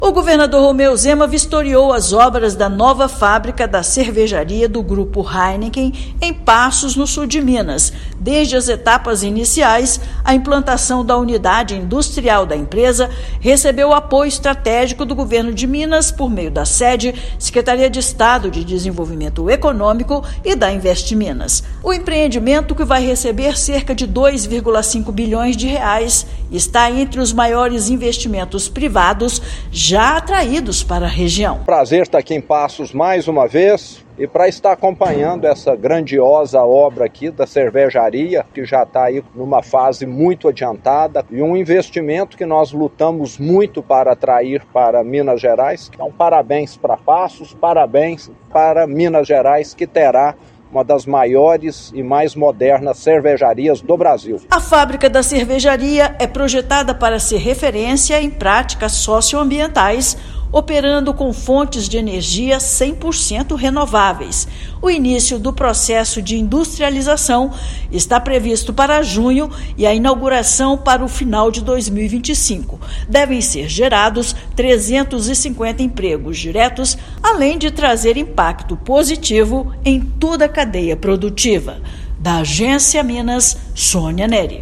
Governador vistoriou as obras da nova fábrica do Grupo Heineken, construída do zero e com previsão de mais 350 empregos diretos na região. Ouça matéria de rádio.